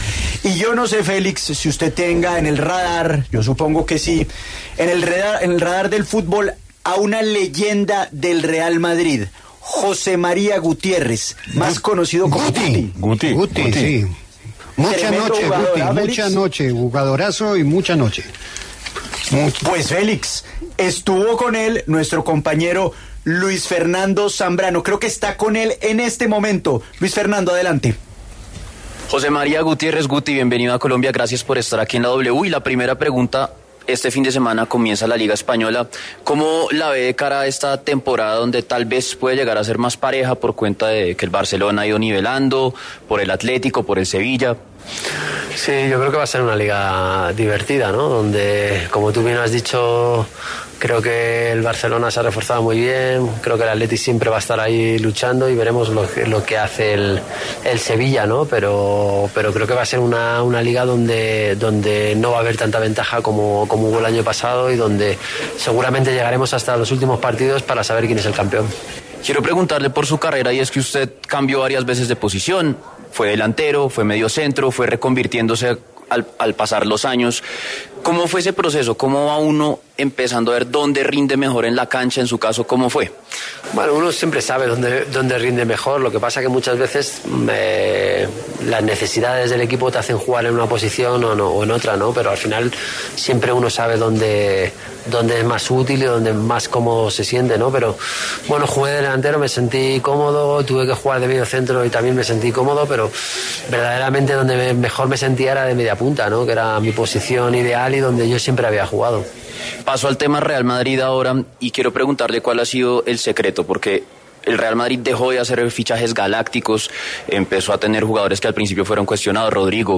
El excentrocampista, que se encuentra en Colombia gracias a RushBet, patrocinador oficial de LaLiga, habló en La W sobre La Liga española, el Mundial de este año y el fútbol femenino en Colombia.